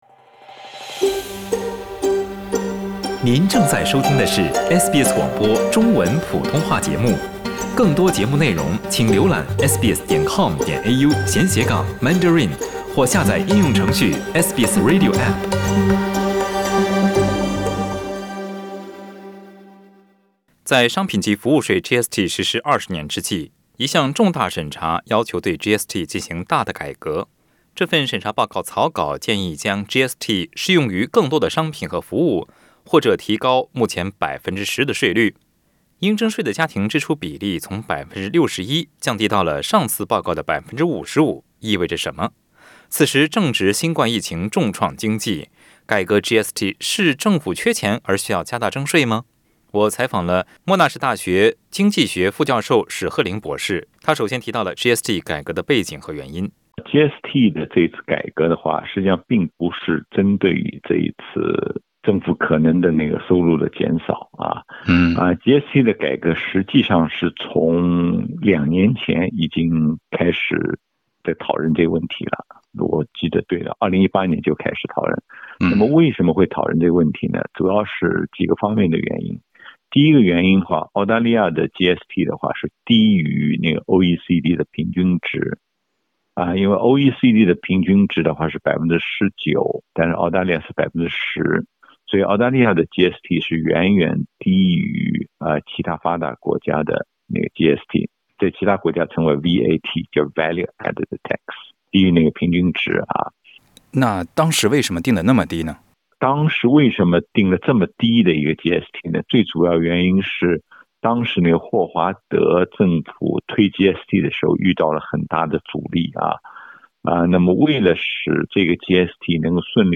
（点击上图收听录音采访）